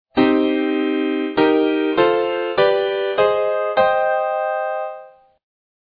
Minor chords hidden in the C major scale